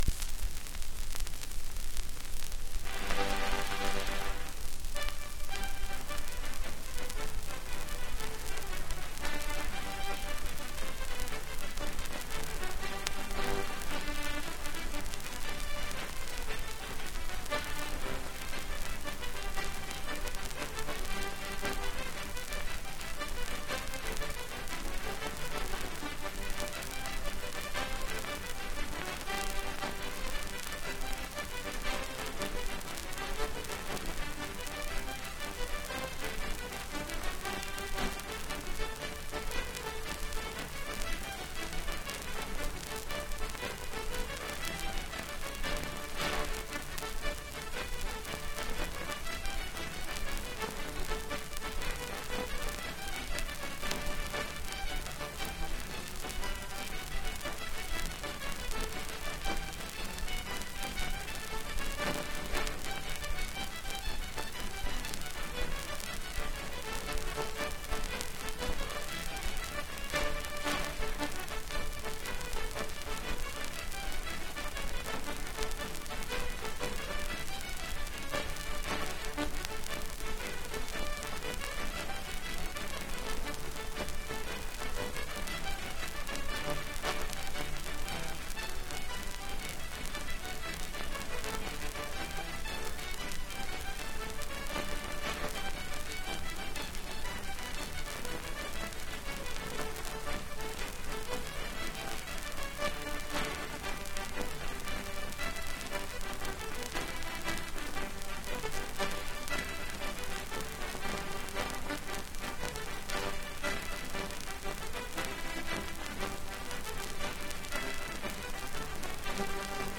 1 10 inch 78rpm shellac disc